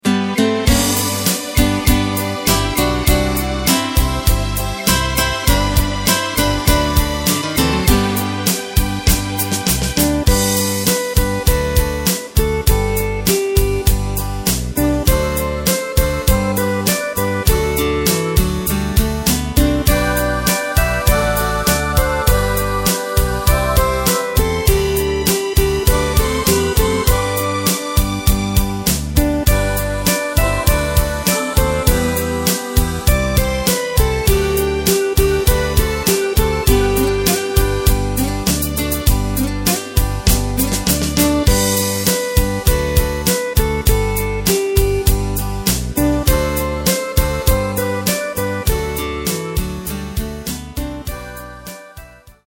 Takt:          4/4
Tempo:         100.00
Tonart:            G
Schlager (Volkslied) aus dem Jahr 1975!
Playback mp3 Demo